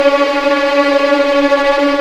Index of /90_sSampleCDs/Roland L-CD702/VOL-1/STR_Vlns Tremelo/STR_Vls Tremolo